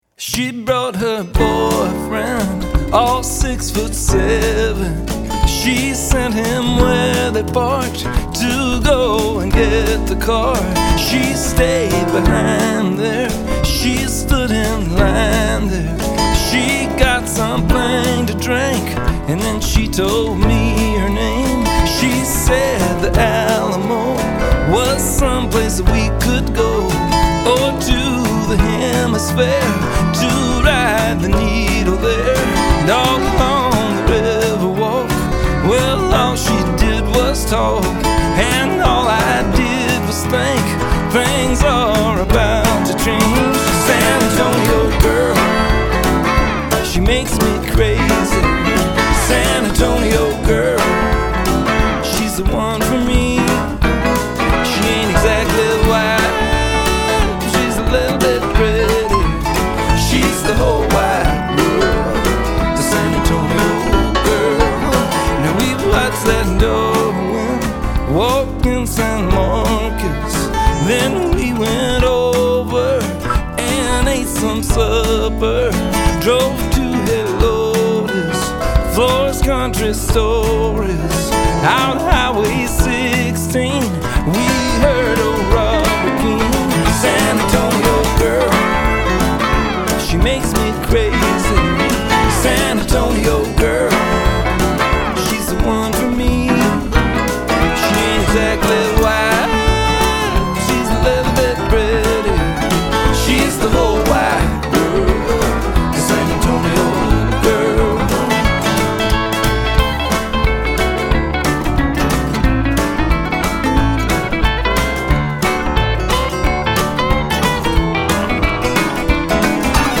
All are well-performed and sound great.